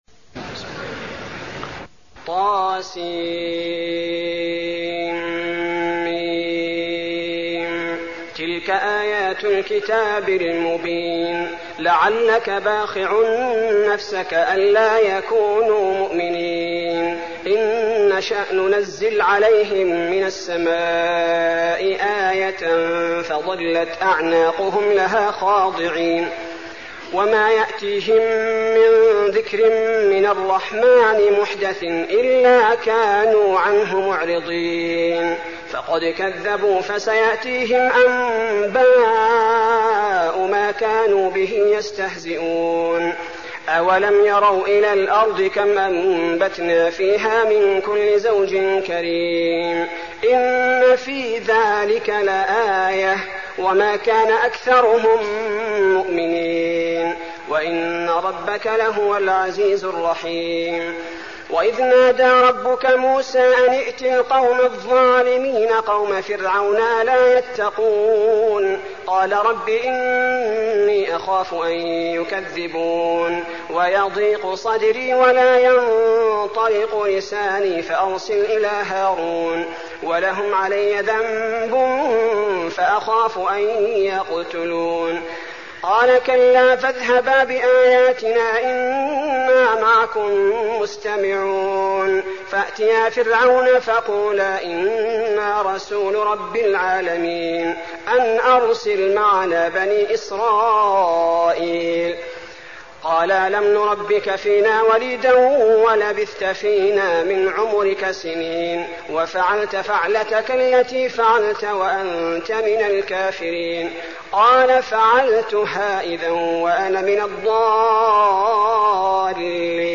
المكان: المسجد النبوي الشعراء